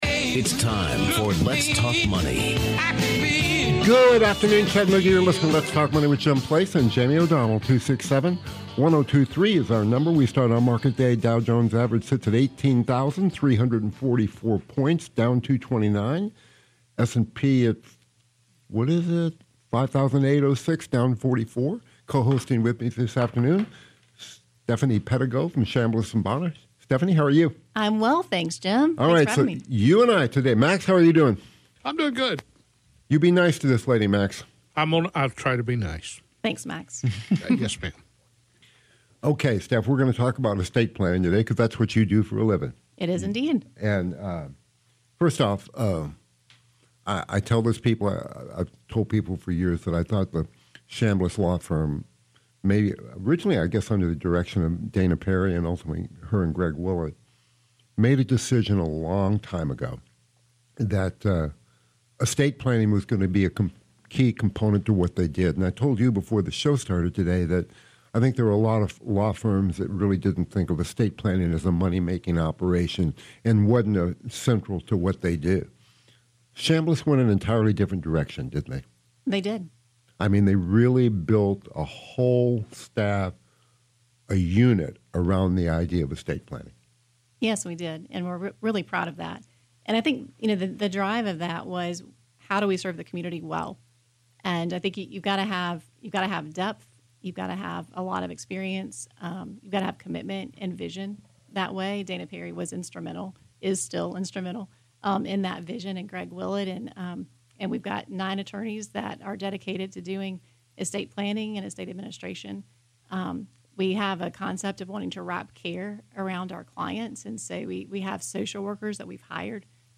Let’s Talk Money is hosted by Evergreen Advisors weekdays from 12 to 1 p.m. on WGOW 102.3FM.